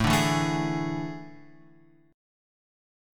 A Major 7th Suspended 4th